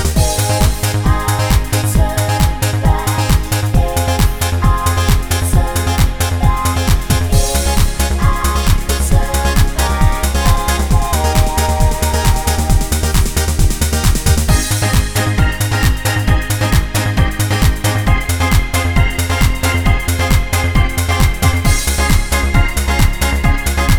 for duet Dance 4:28 Buy £1.50